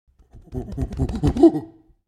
دانلود صدای میمون 12 از ساعد نیوز با لینک مستقیم و کیفیت بالا
جلوه های صوتی